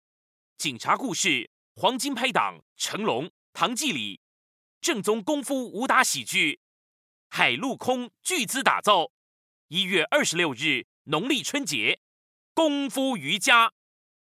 MN SF MOV 01 TV shows and Movies Male Mandarin